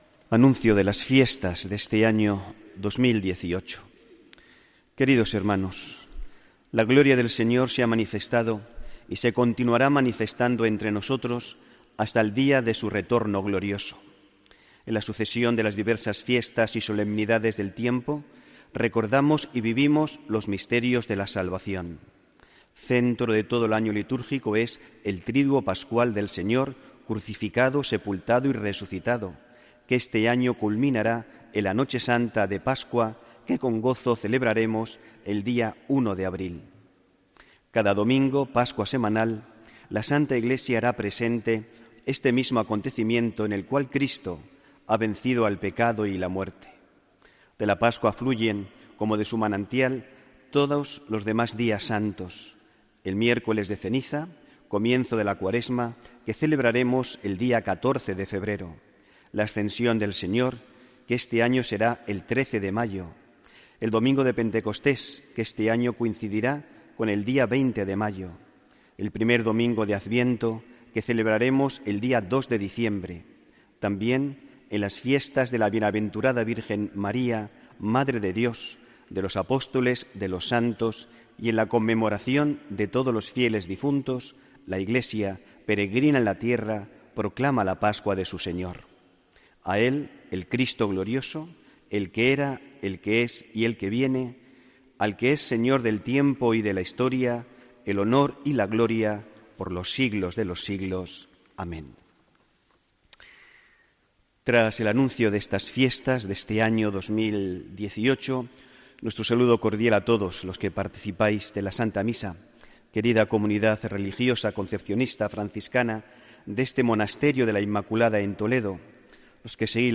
HOMILÍA 6 ENERO 2018